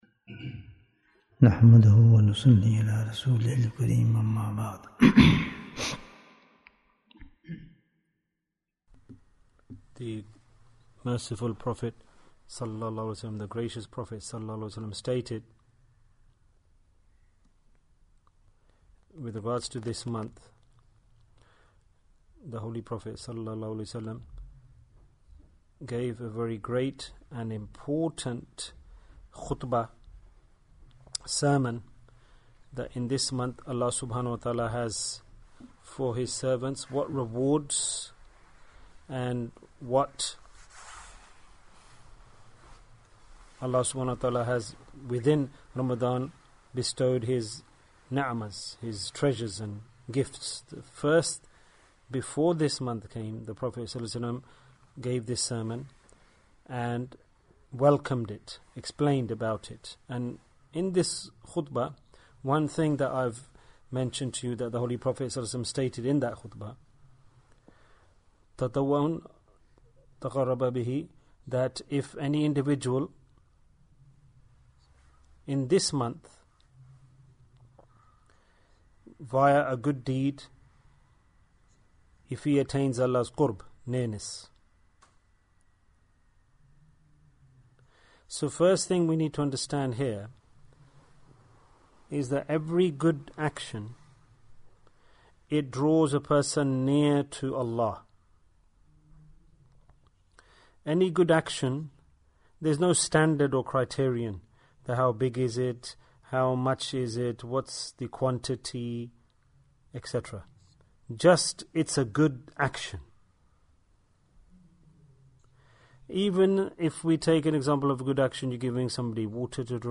The Harm of Negligence in Ramadhan Bayan, 40 minutes26th March, 2023